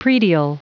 Prononciation du mot predial en anglais (fichier audio)
Prononciation du mot : predial